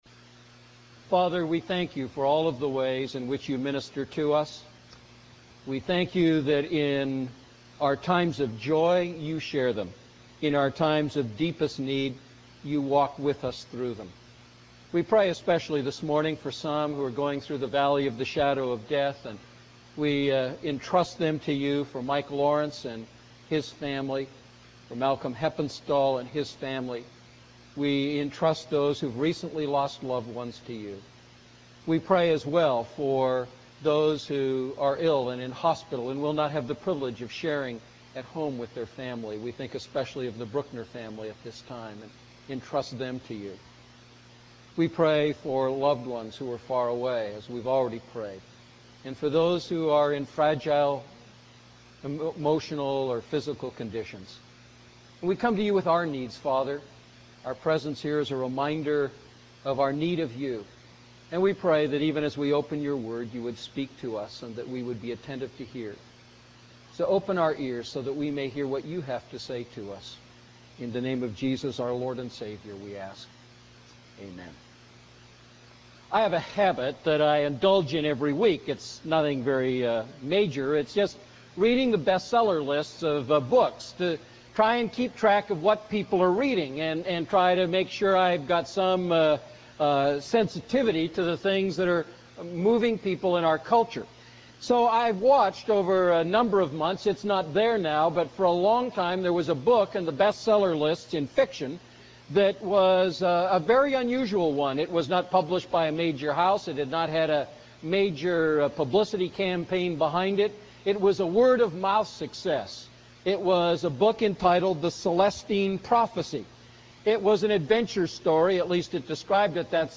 A message from the series "Miscellaneous."